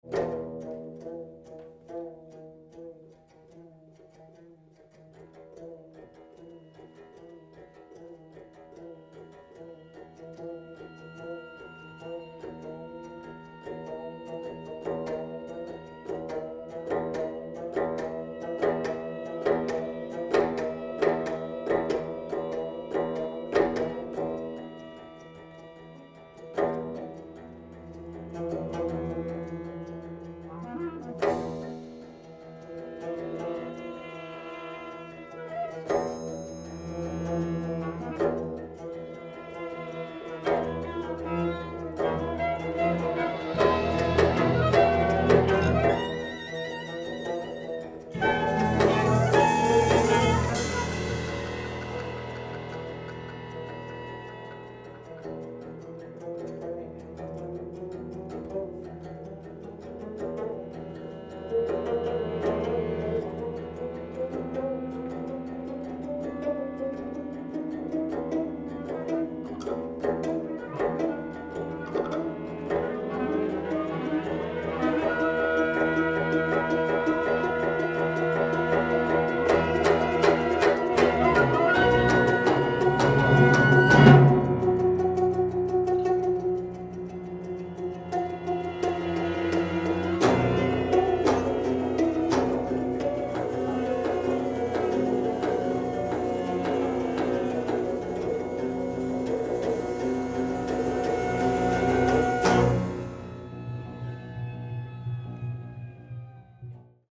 geomungo concerto